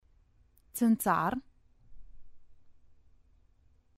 țânțar